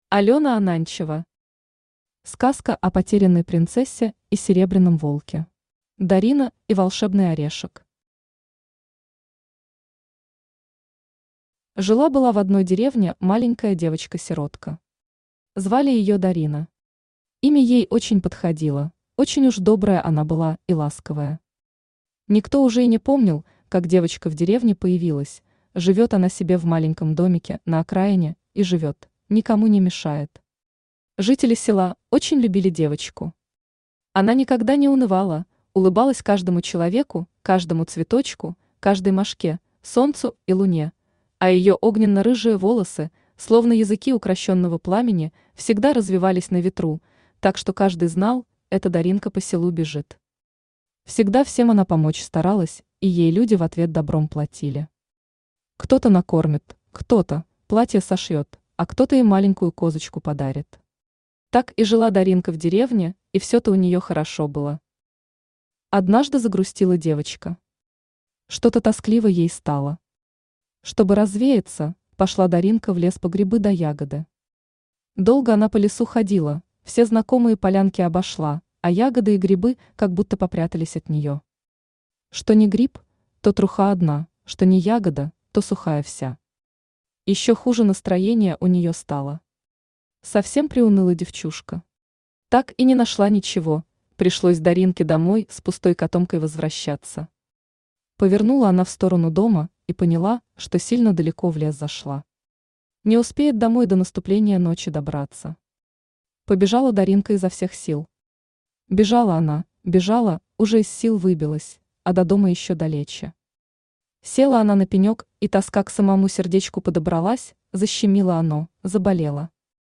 Аудиокнига Сказка о потерянной принцессе и серебряном волке | Библиотека аудиокниг
Aудиокнига Сказка о потерянной принцессе и серебряном волке Автор Алена Ананчева Читает аудиокнигу Авточтец ЛитРес.